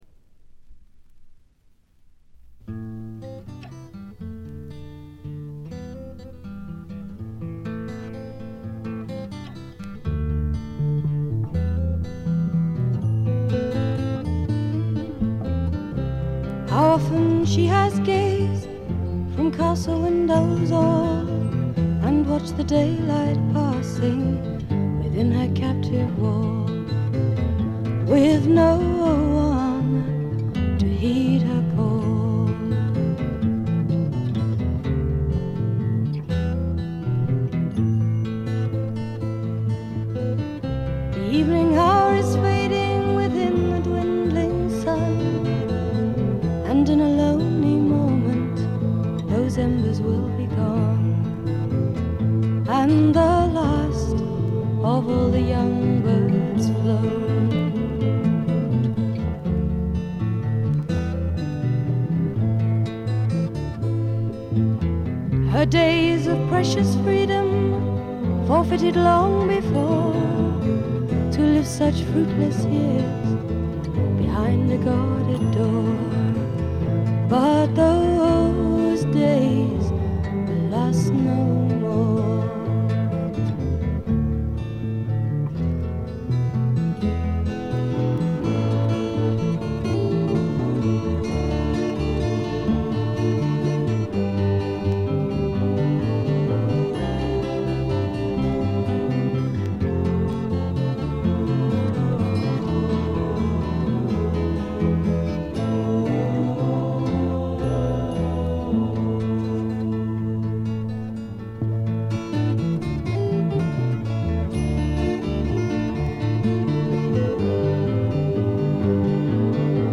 極めて良好に鑑賞できます。
英国フォークロック基本中の基本。
試聴曲は現品からの取り込み音源です。